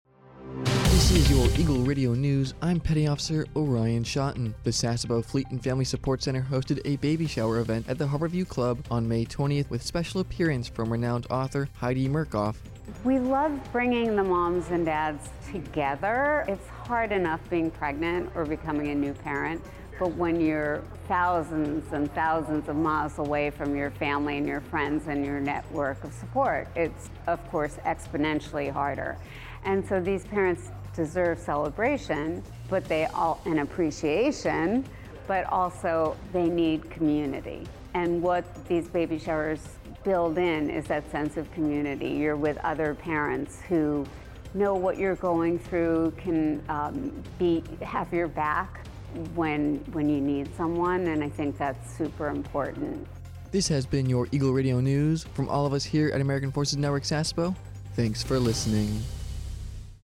A TFN newscast for AFN Sasebo's radio about the Sasebo Fleet and Family Support Center who hosted a baby shower event at the Harbor View Club on May 20, 2024.